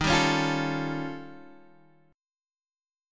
Eb7#11 chord